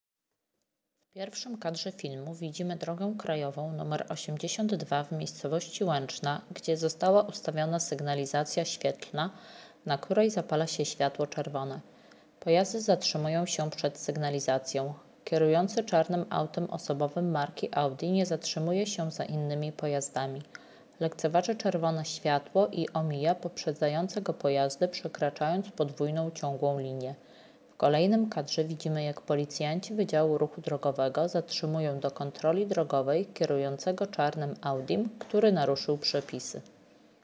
Nagranie audio Audiodyskrypcja filmu Nagranie z policyjnego drona